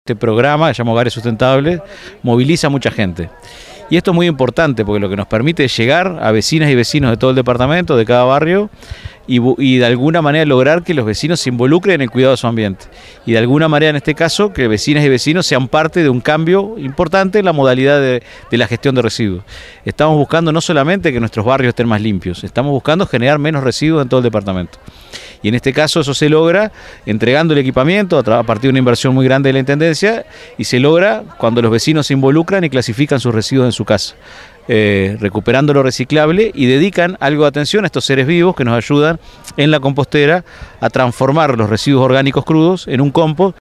director_gestion_ambiental_leonardo_herou.mp3